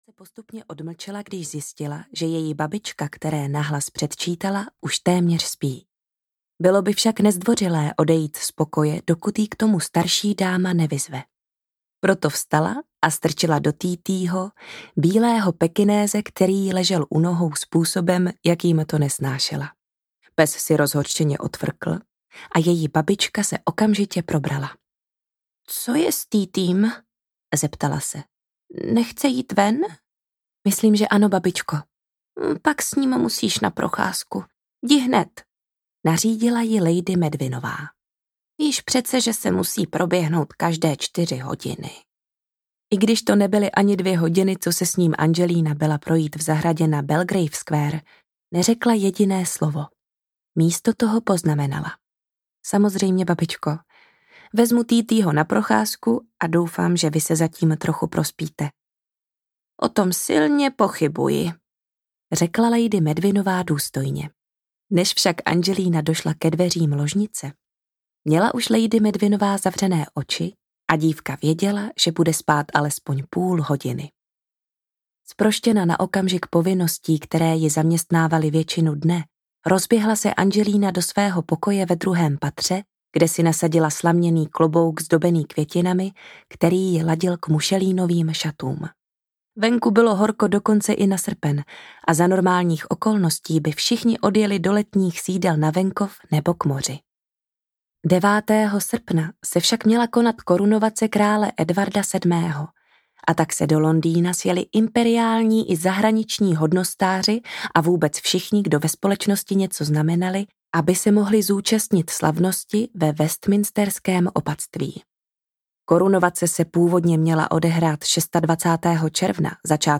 Princ a psík audiokniha
Ukázka z knihy